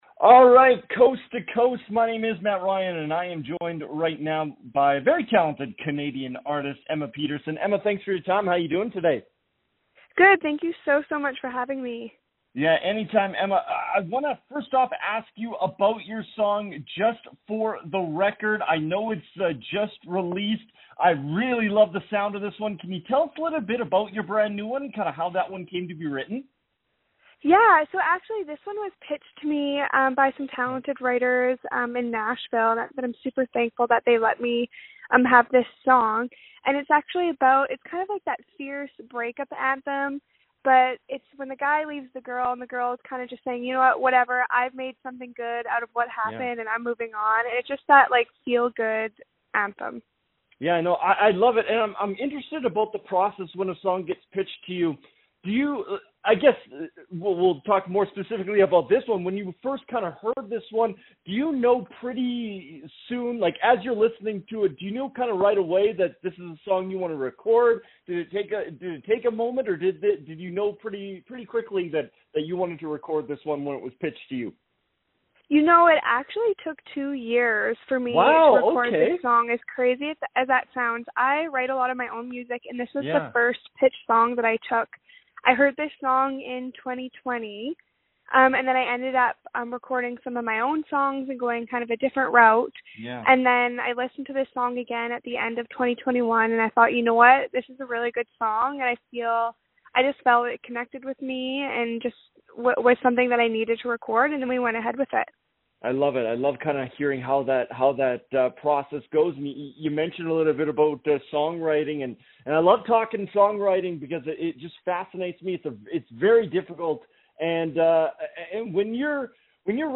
joined me on the phone to talk new music and what is on the way.